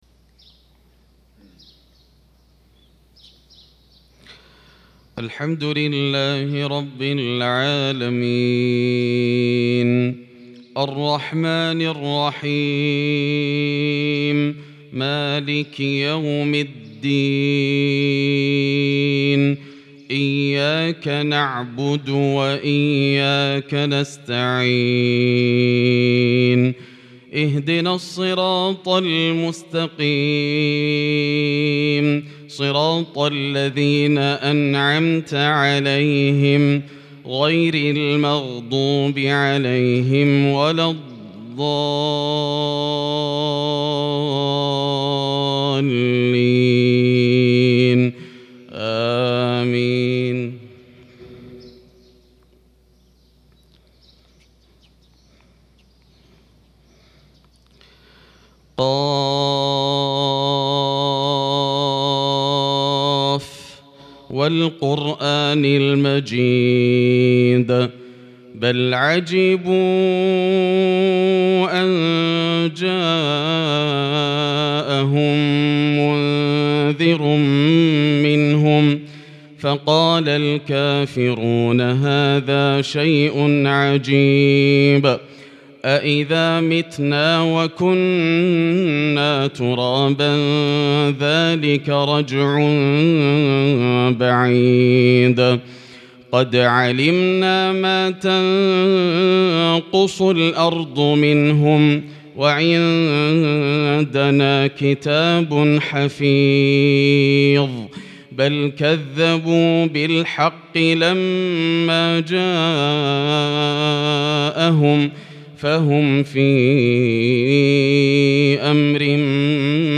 صلاة الفجر للقارئ ياسر الدوسري 9 ربيع الأول 1443 هـ
تِلَاوَات الْحَرَمَيْن .